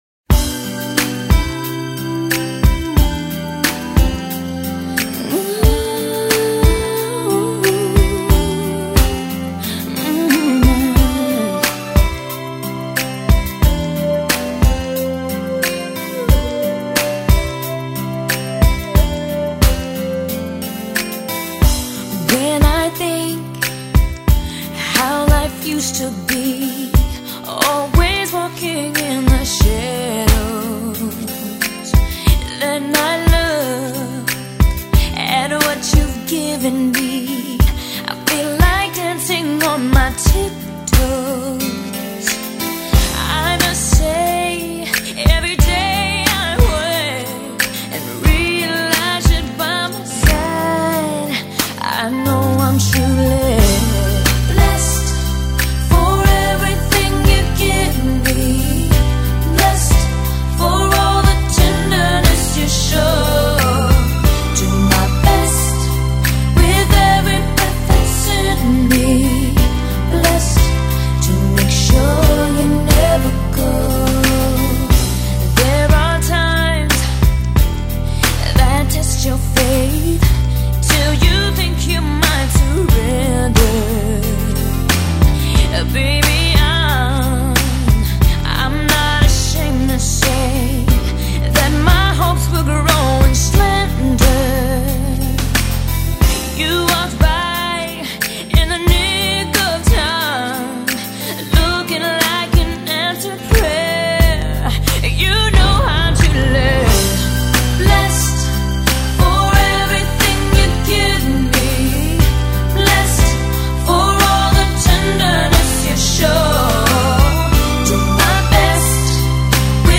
Music Soundtrack – Latin/Pop Flava “Double-Play”